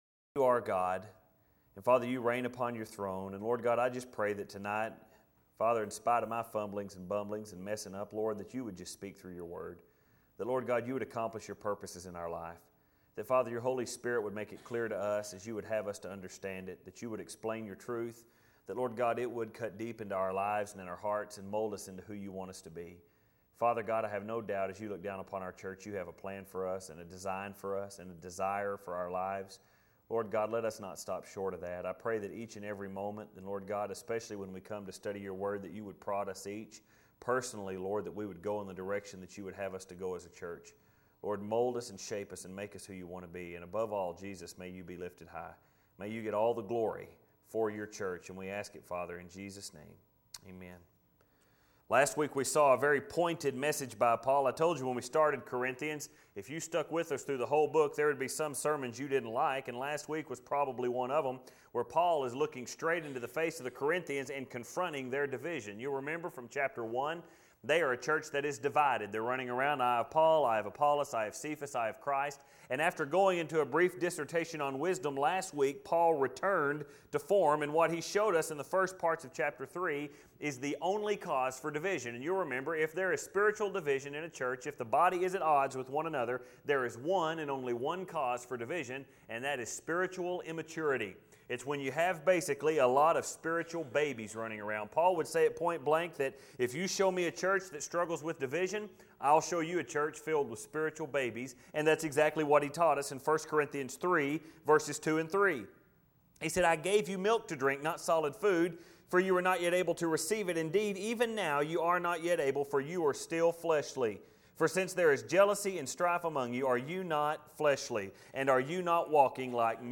Filed Under: Sermons Tagged With: Corinthians